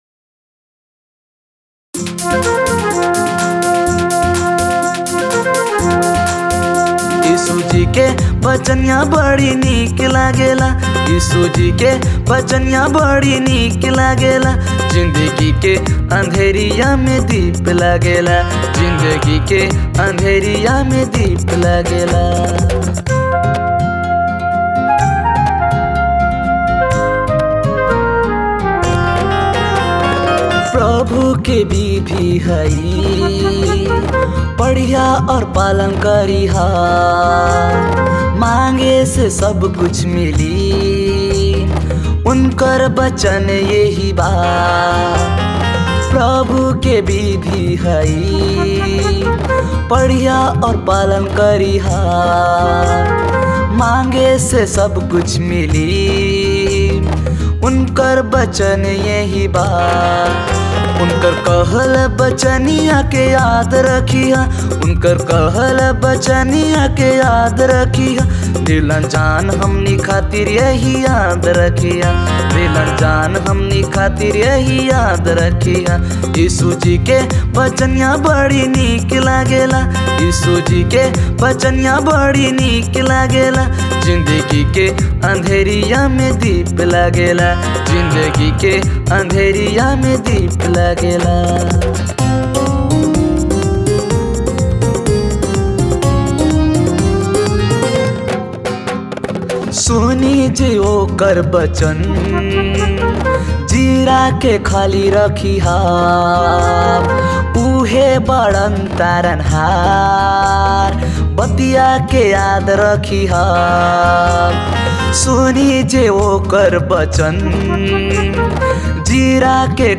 Royalty-free Christian music available for free download.
Royalty free Christian music.